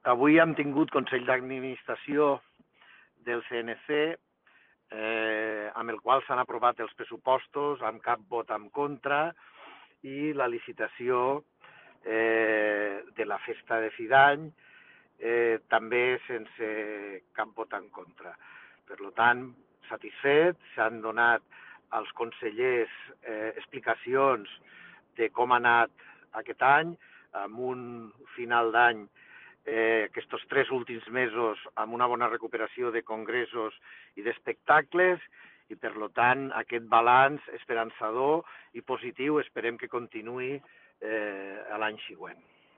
tall-de-veu-del-tinent-dalcalde-paco-cerda-sobre-el-consell-dadministracio-del-cnc